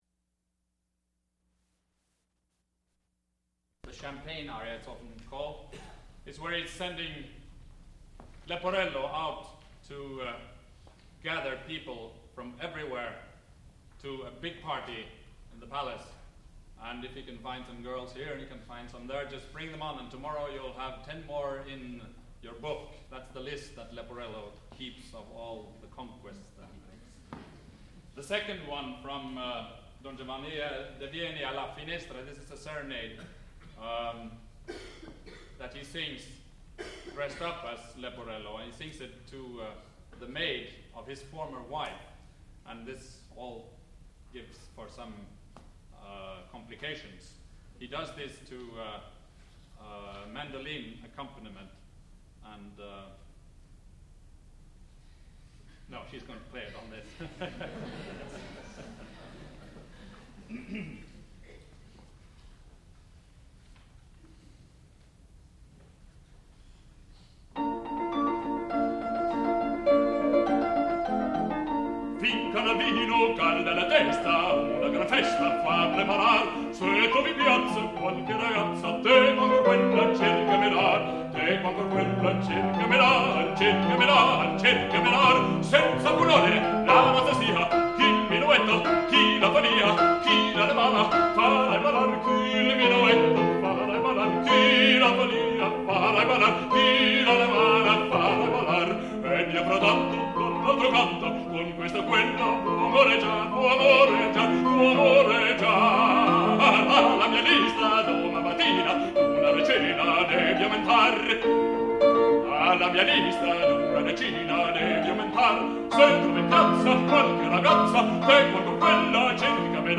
sound recording-musical
classical music
baritone
piano